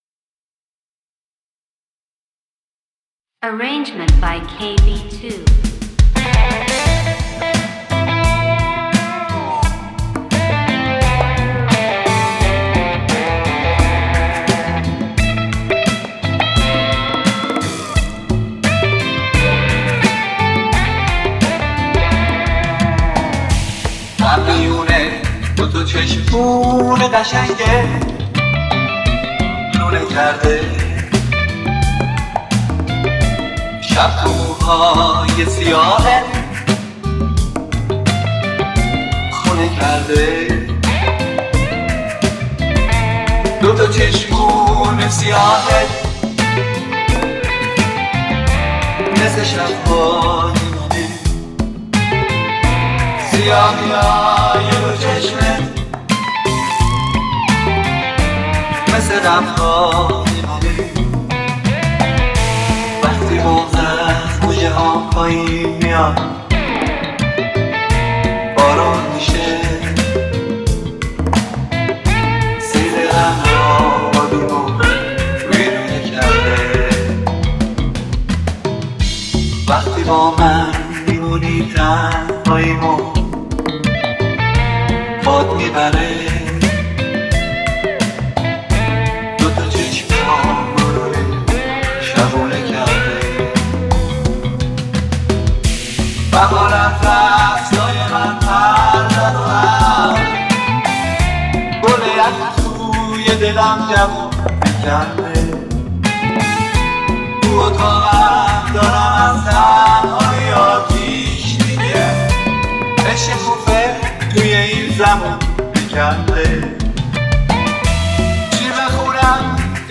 با سبک راک